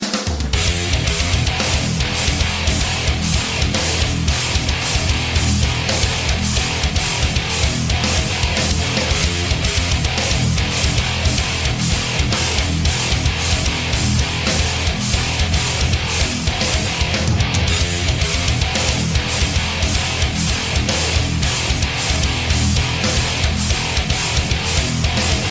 Тяжелый рок